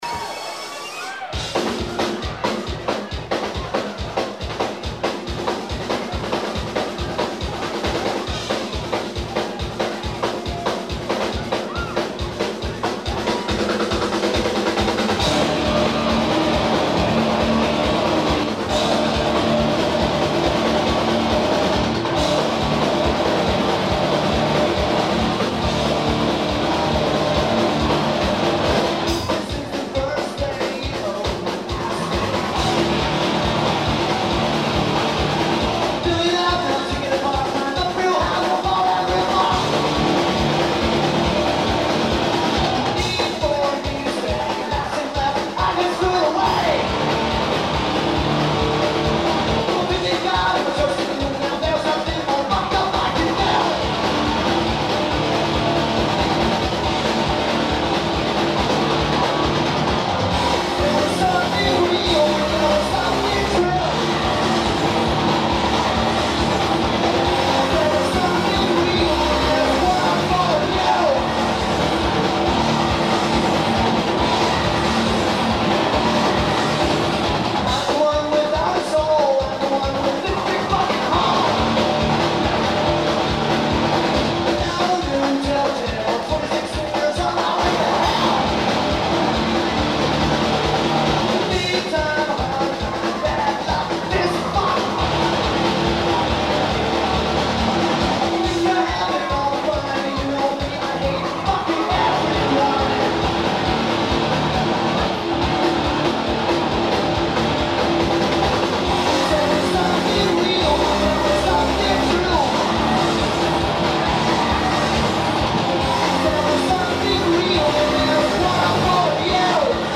Tipitina's
Drums
Guitar
Vocals/Guitar/Keyboards